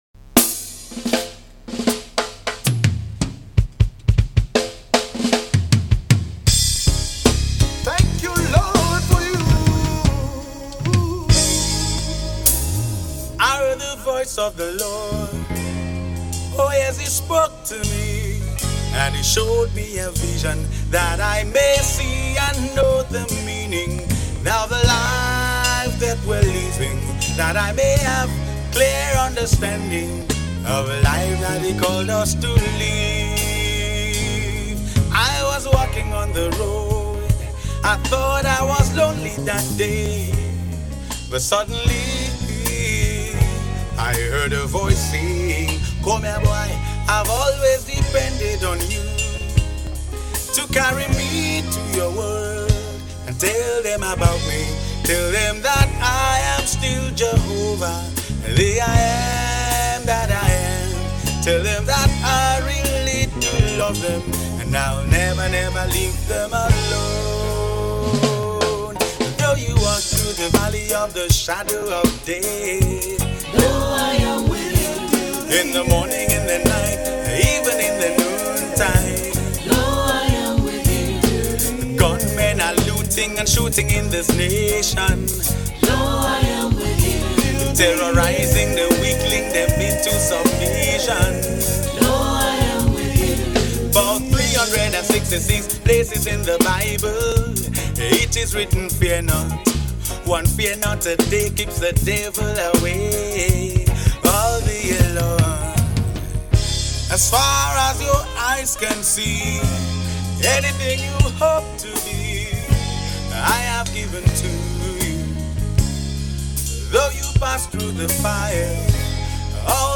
March 12, 2025 Publisher 01 Gospel 0
poet spoken words and reggae gospel artist.